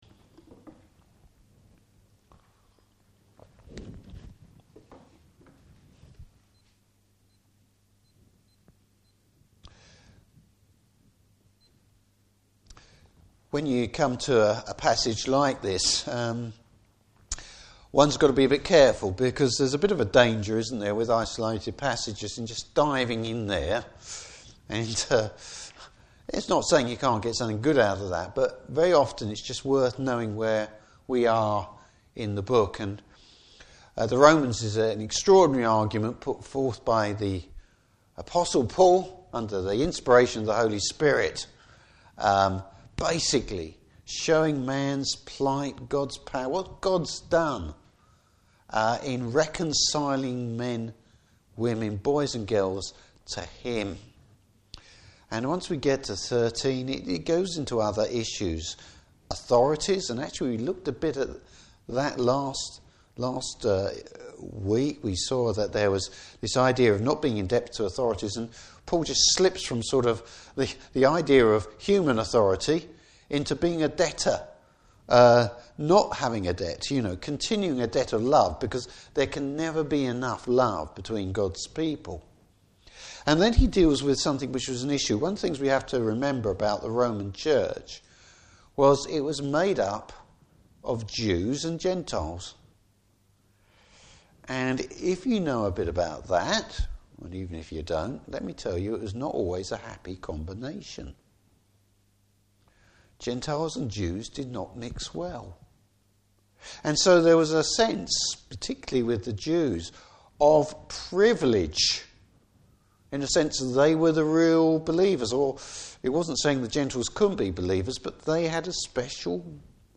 Service Type: Evening Service Bible Text: Romans 15:4-13.